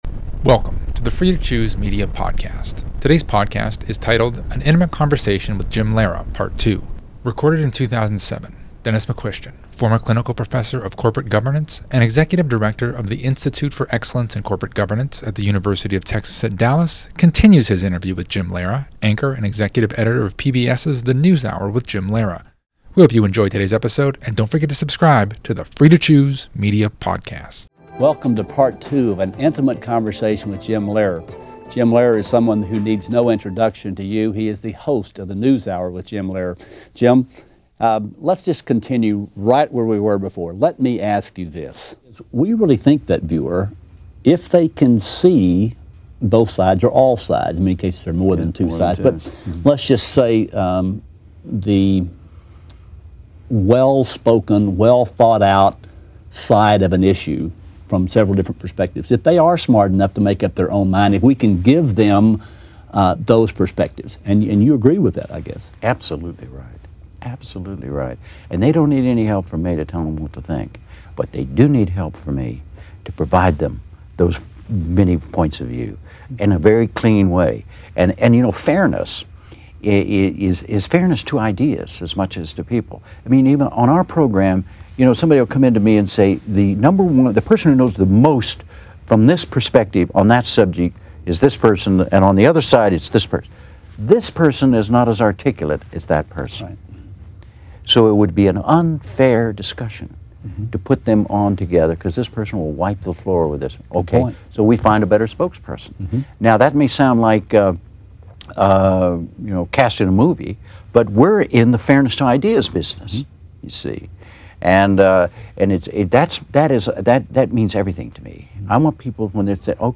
Episode 246 – An Intimate Conversation with Jim Lehrer, Part Two